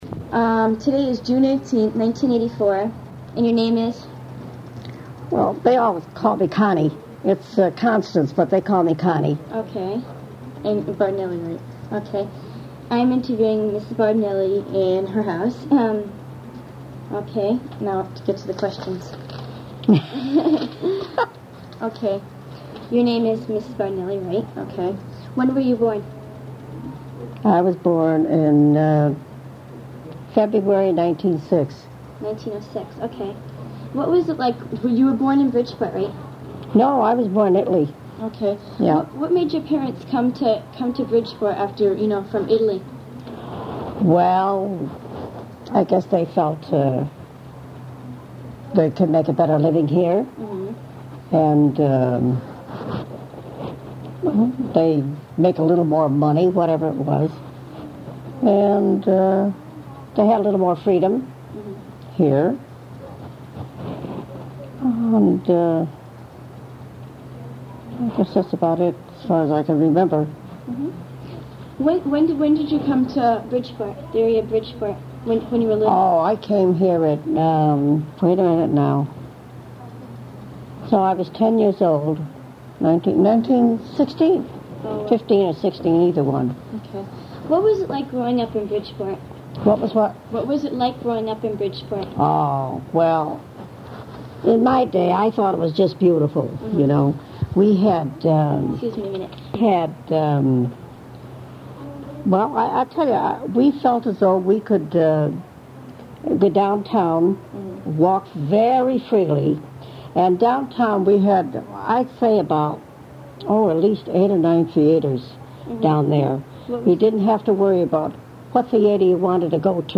Bridgeport Living History : Oral Histories Conducted by Bridgeport Youth in 1984